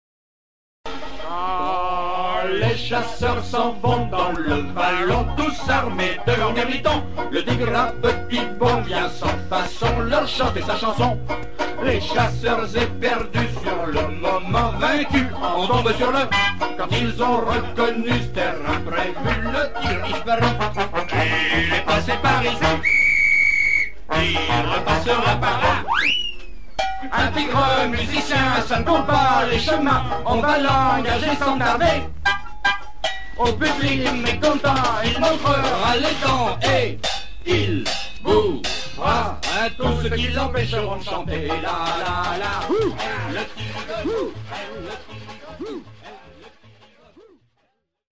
trombone
Enregistrement public au Petit Journal